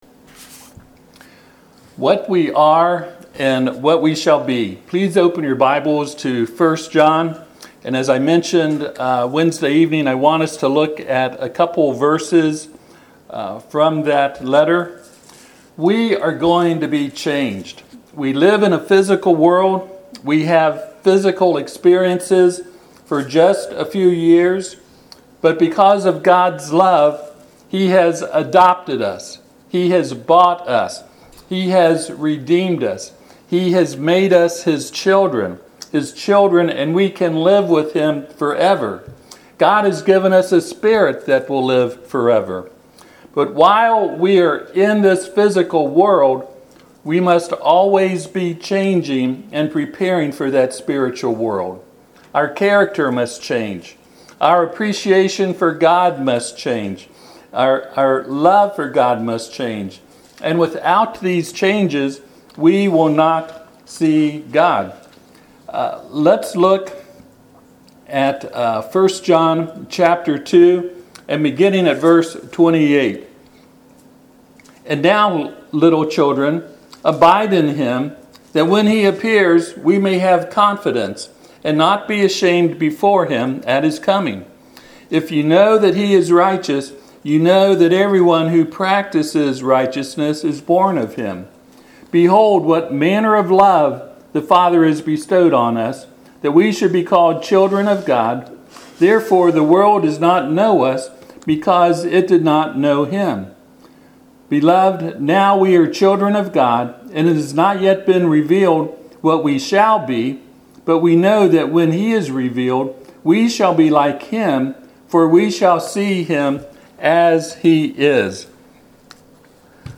1 John 3:1-2 Service Type: Sunday AM What we are and what we shall be. 1John 3:1-2 We shall all be changed.